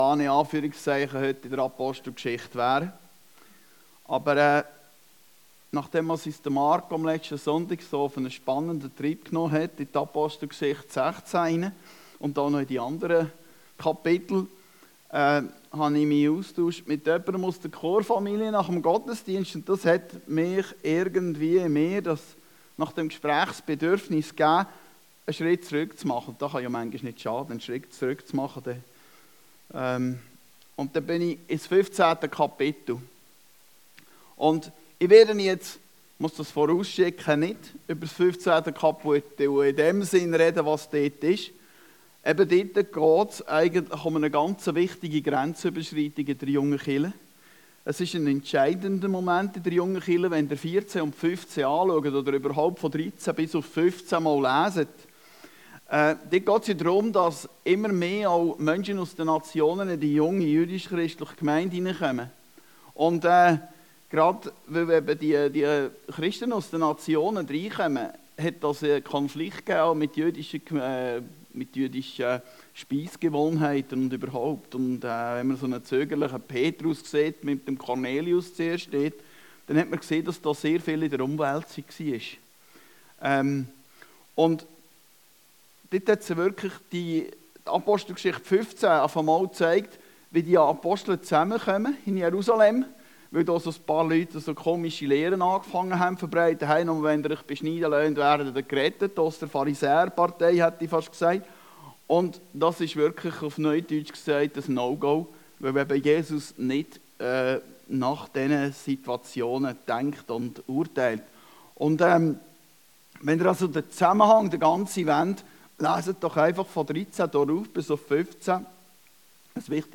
Predigten Heilsarmee Aargau Süd – BERUFEN UND GEFÜHRT